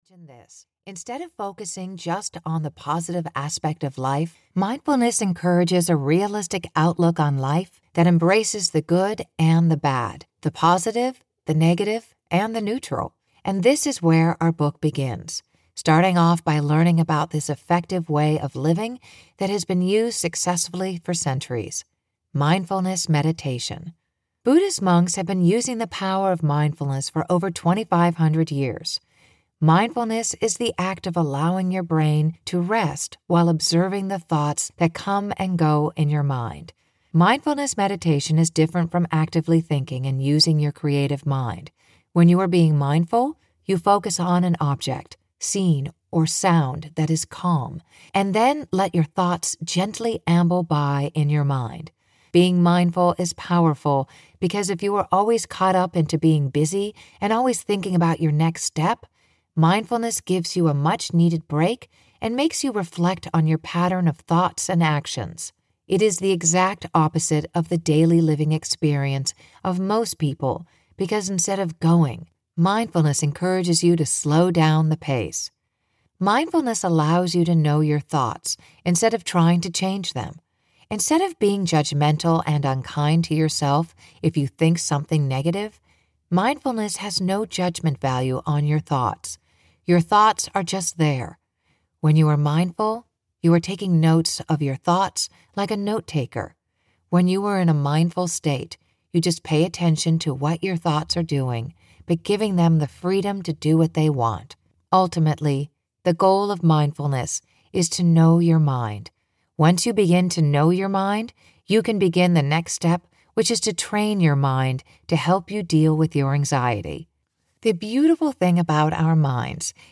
Mindfulness (EN) audiokniha
Ukázka z knihy